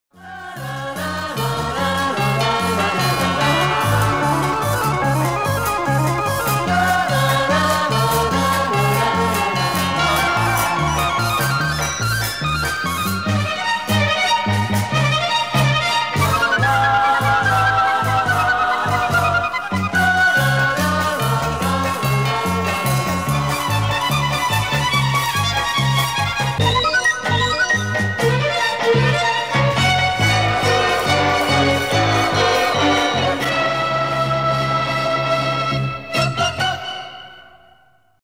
Звук обновленной заставки